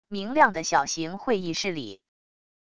明亮的小型会议室里wav音频